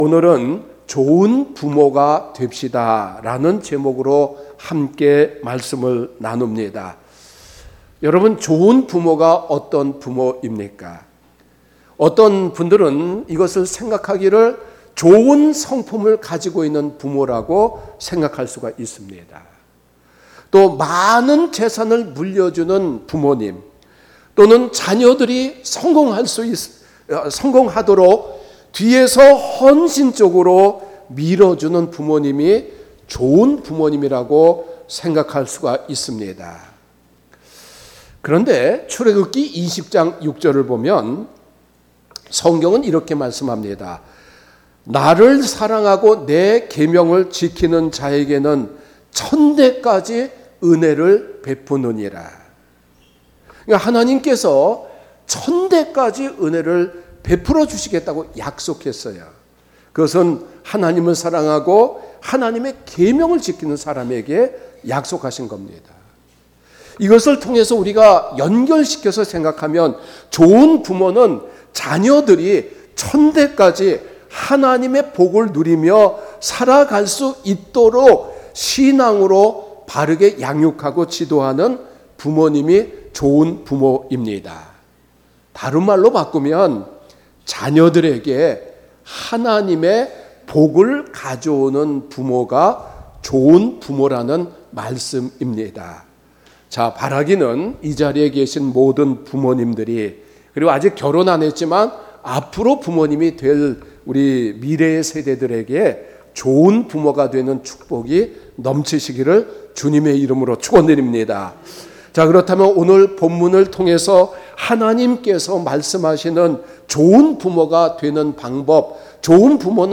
주일설교